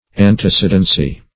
antecedency - definition of antecedency - synonyms, pronunciation, spelling from Free Dictionary
Antecedency \An`te*ced"en*cy\, n.